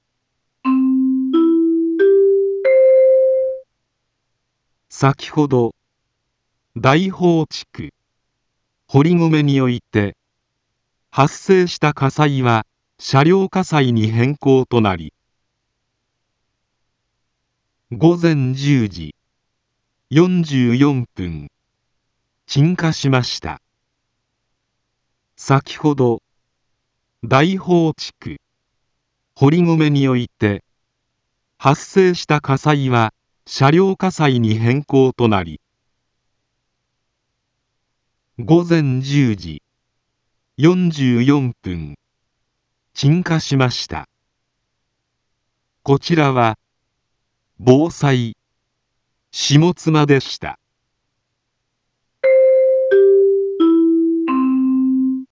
一般放送情報
BO-SAI navi Back Home 一般放送情報 音声放送 再生 一般放送情報 登録日時：2024-05-16 11:06:58 タイトル：鎮火報 インフォメーション：先程、大宝地区、堀篭において、発生した火災は、車両火災に変更となり 午前１０時、４４分、鎮火しました。